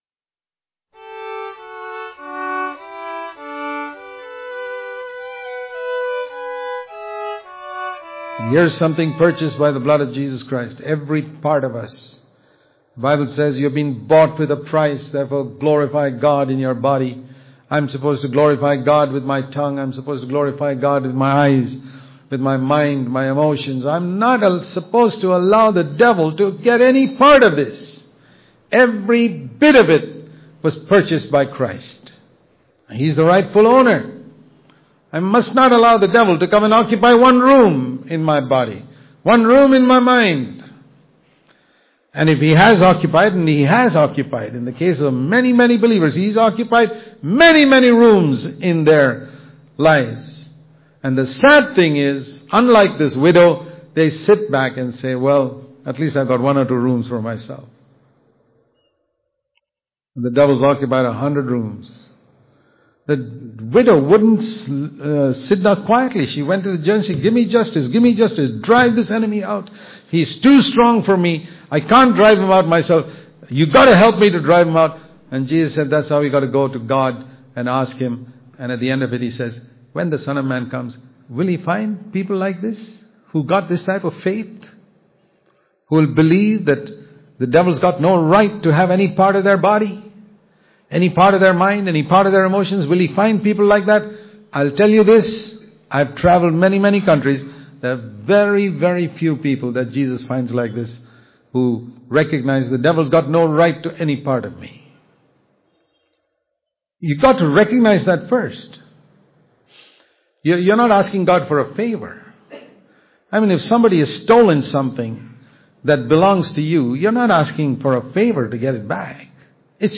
October 31 | Daily Devotion | The Devil Has No Right To Any Part Of Me | cfcindia, Bangalore